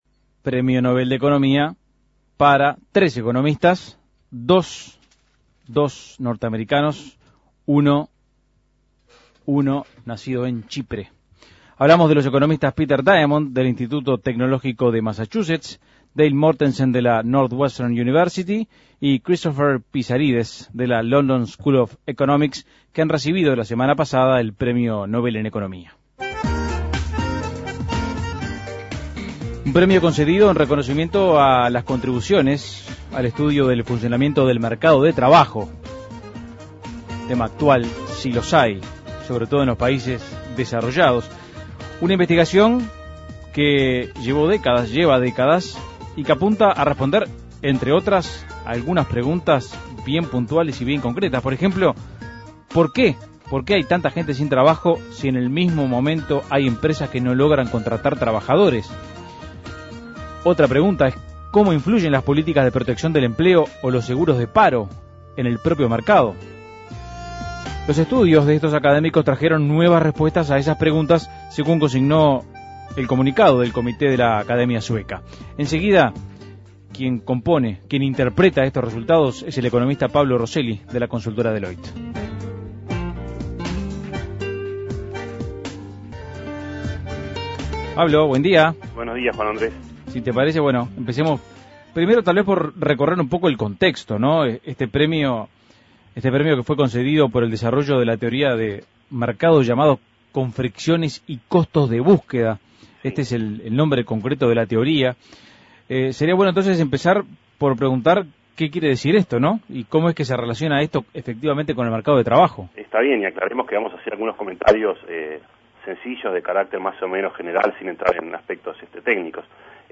Análisis Económico Premio Nobel de Economía a tres investigadores del mercado de trabajo: ¿cuáles fueron sus principales contribuciones?